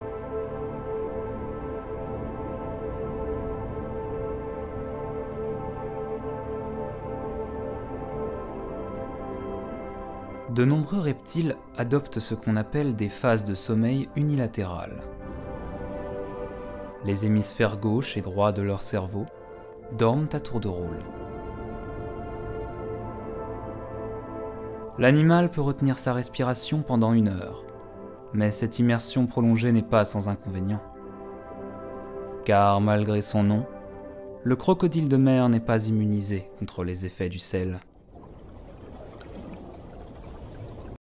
Extrait Publicité Mercedes
12 - 30 ans - Baryton Ténor